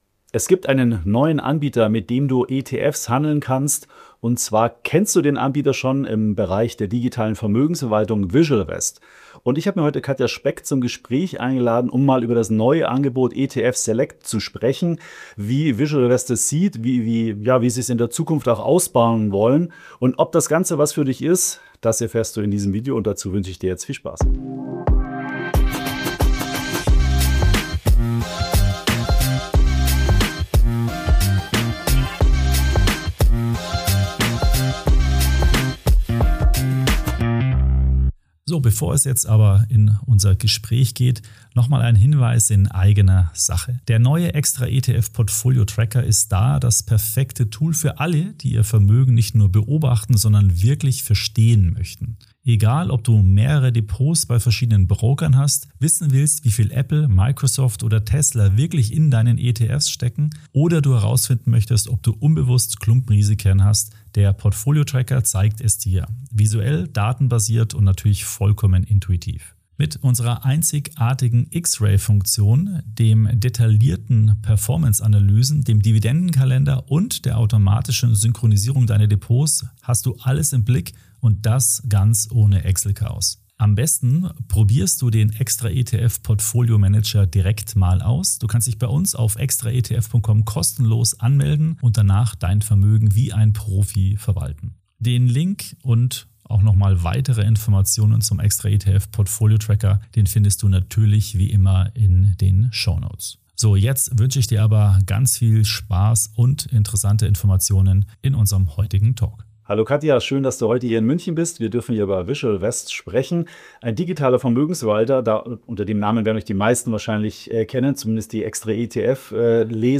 Warum weniger Auswahl mehr Orientierung bedeuten kann, wie sich VisualVest von anderen Neobrokern unterscheidet und welche Rolle Technologiepartner Upvest dabei spielt – all das erfährst du in dieser Podcastfolge. Ein Gespräch über Freiheit beim Investieren, Verantwortung und langfristigen Vermögensaufbau.